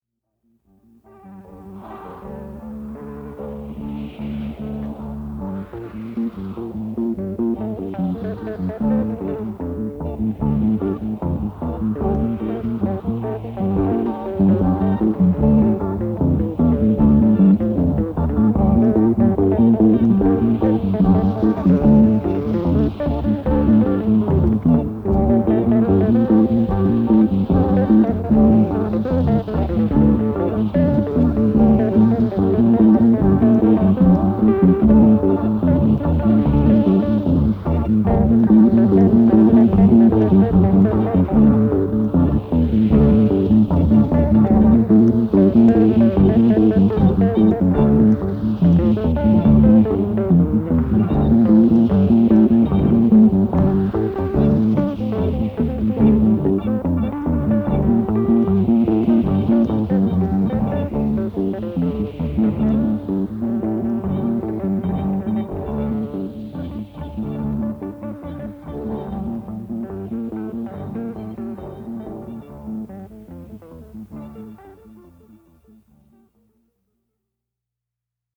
What sounds like guitar is bass recorded at 3 3/4 ips and played back at 7 1/2.